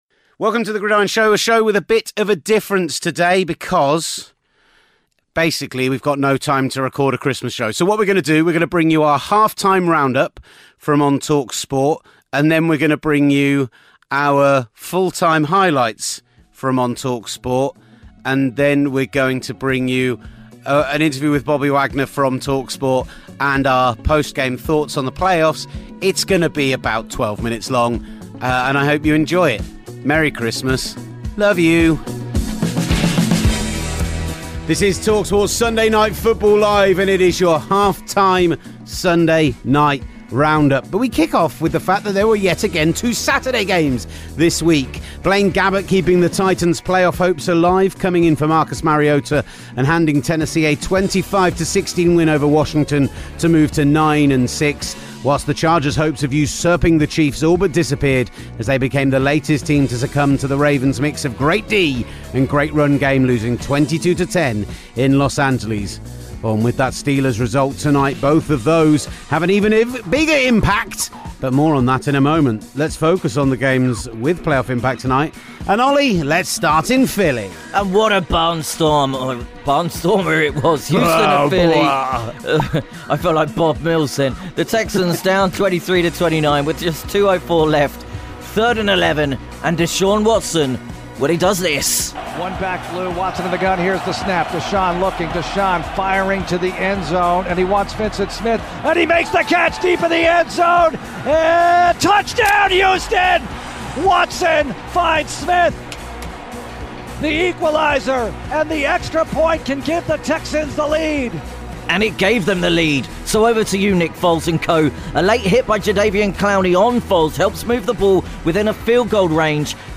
With no time to record a podcast on Xmas eve we've popped together some highlights from our Sunday night show on talkSPORT including Westwood One's chat with Bobby Wagner and our thoughts on the playoff race.